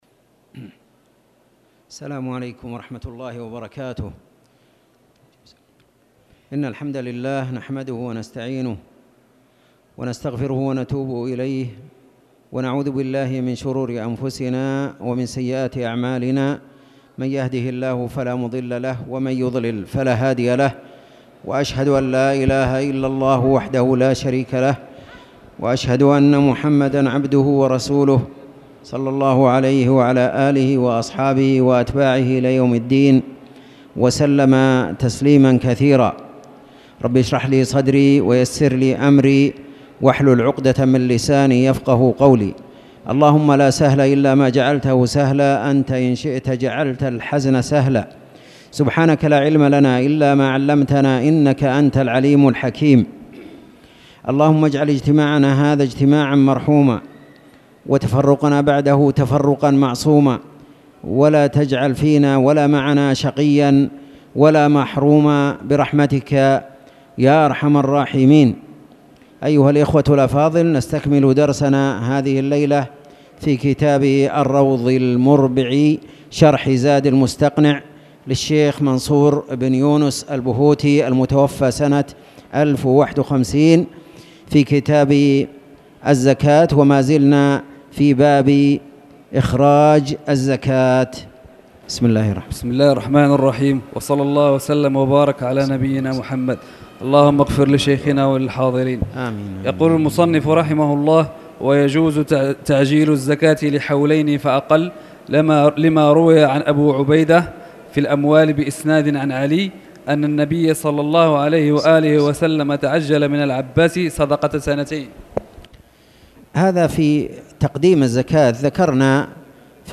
تاريخ النشر ٨ صفر ١٤٣٨ هـ المكان: المسجد الحرام الشيخ